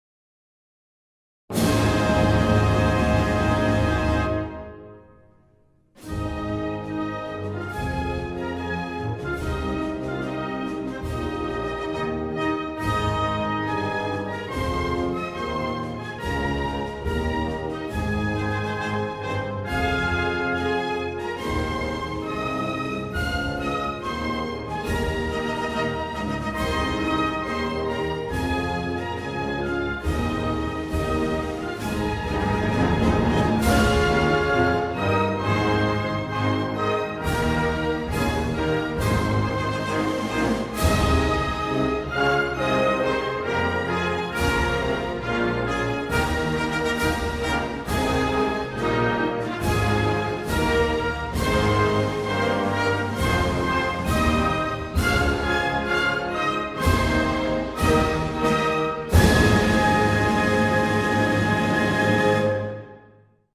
Mongolian_anthem.mp3